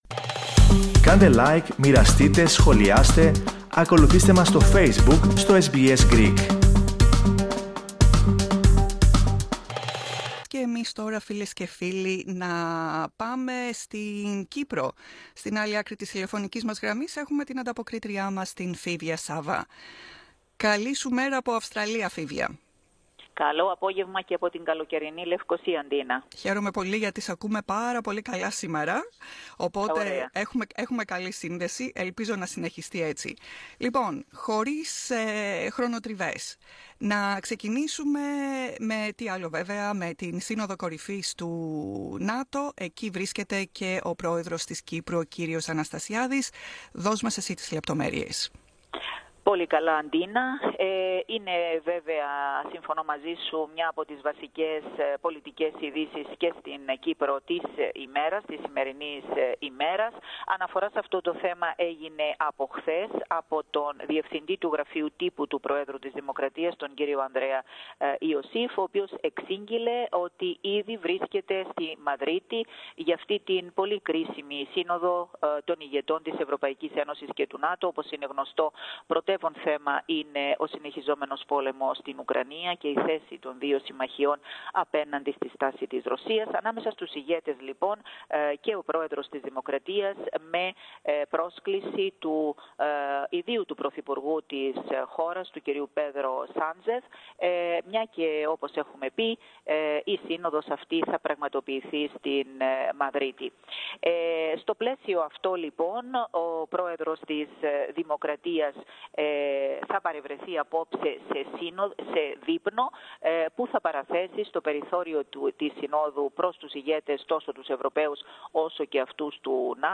Ακούστε ολόκληρη την ανταπόκριση από την Κύπρο, πατώντας στο μέσο της κεντρικής φωτογραφίας.
antapokrisi_podcast.mp3